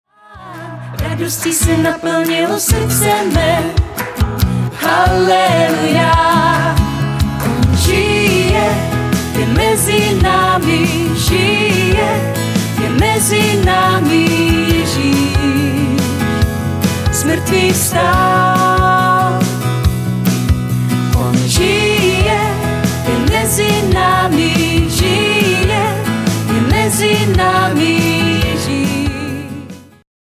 Sborový zpěv: